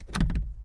Song: SFX DOOR Locked Metal Door Locked Try Open mono